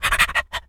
dog_sniff_breathe_04.wav